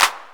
808-Clap05.wav